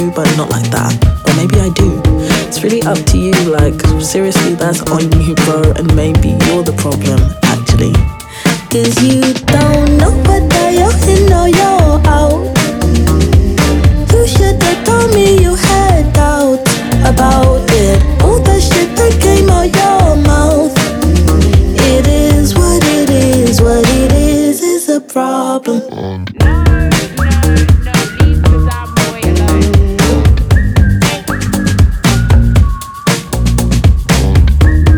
Жанр: Альтернатива